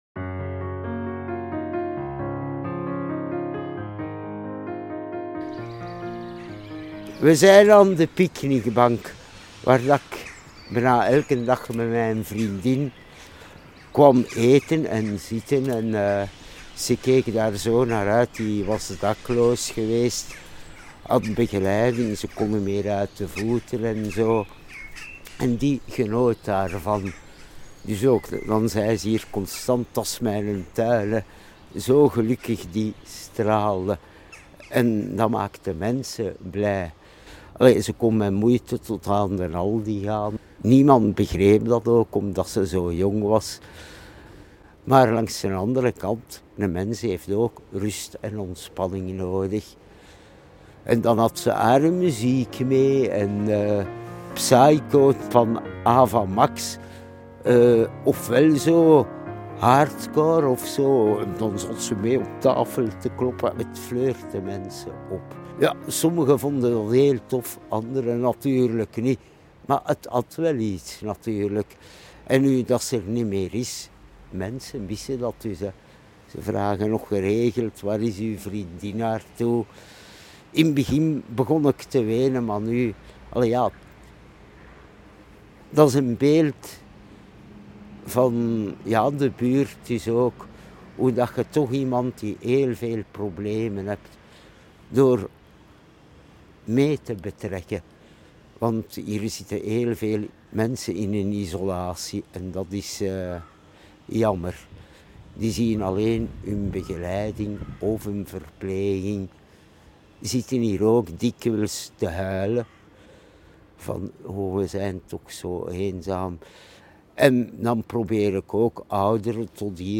Elk van hen nam ons ook mee naar een plek in de buurt waar zij zich goed voelen. Daar maakten we telkens een audioverhaal.